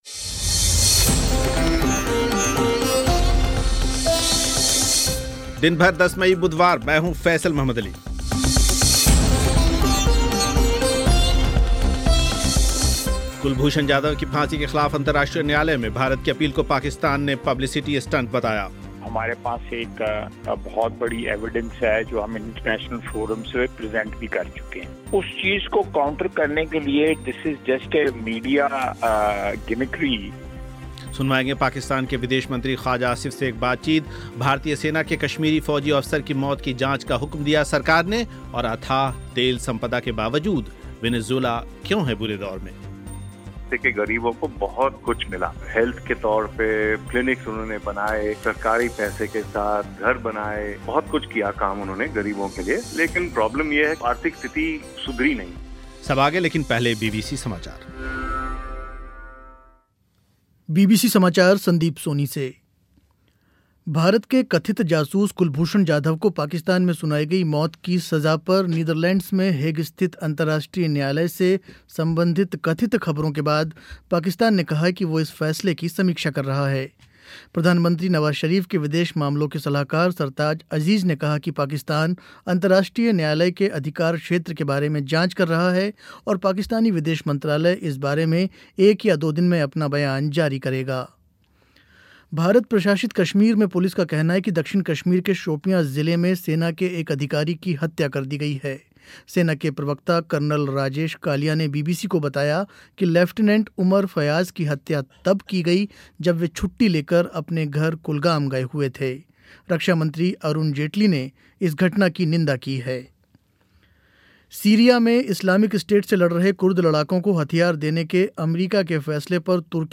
सुनवाएंगे पाकिस्तान के विदेश मंत्री ख़्वाजा आसिफ़ से एक बातचीत